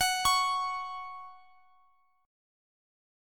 Listen to F#5 strummed